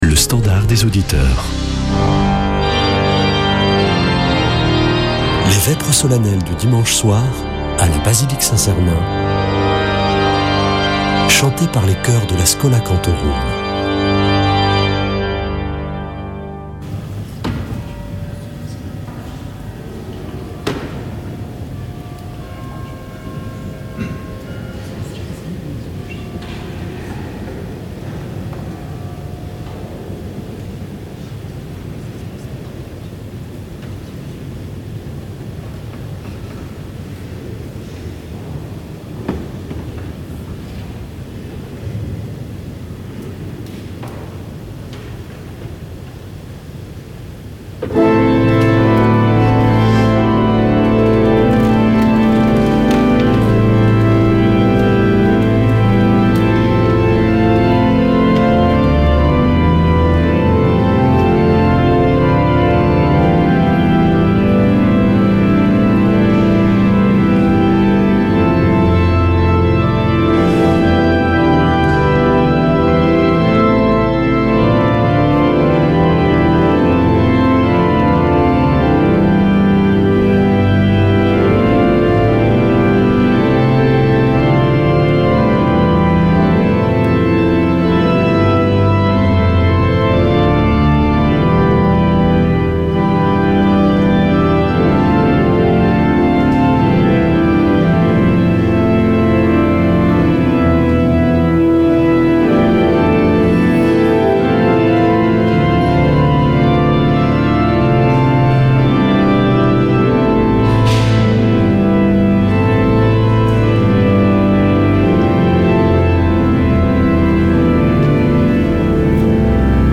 Vêpres de Saint Sernin du 08 oct.